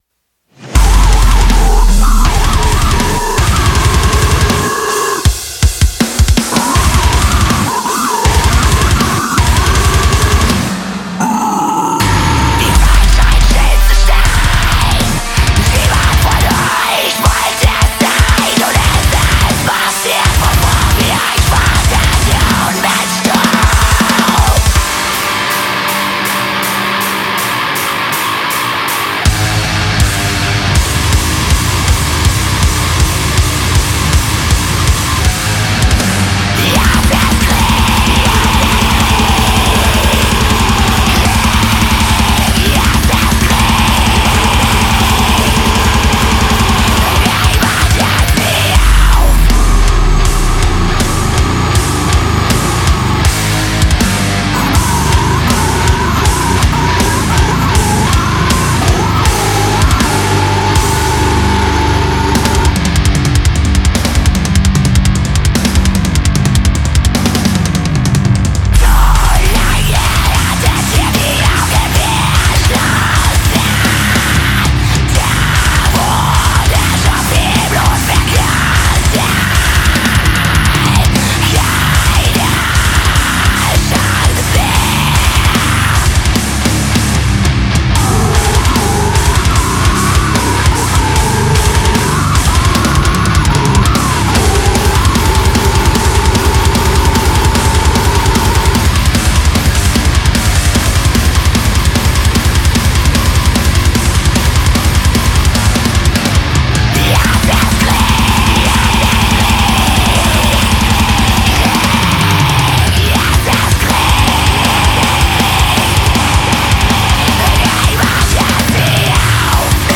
Grind/Deathcore